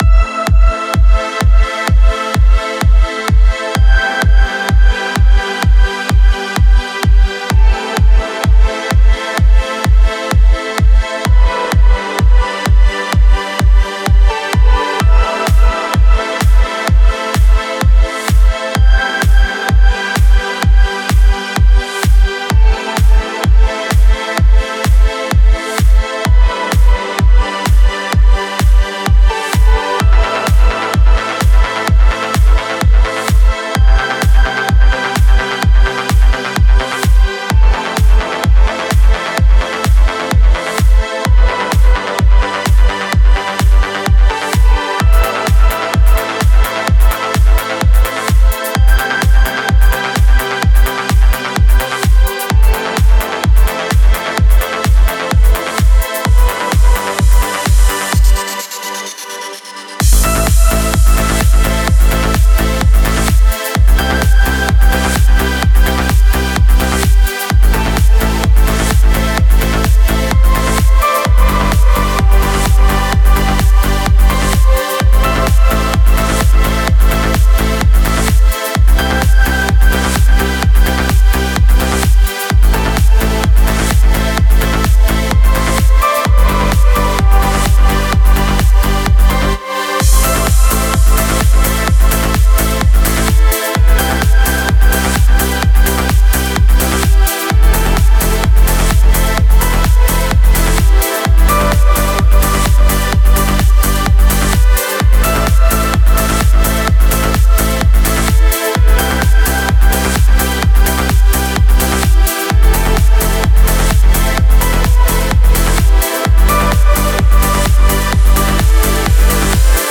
Стиль: Trance / Progressive Trance